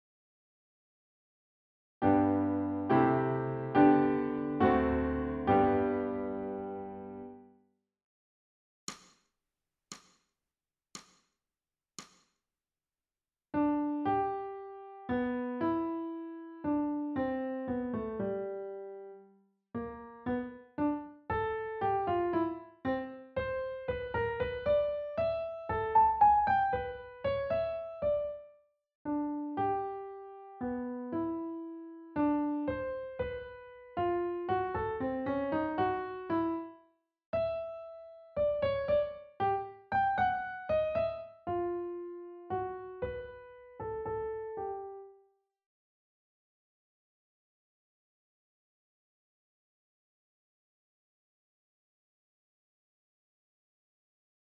ソルフェージュ 聴音: 2-1-39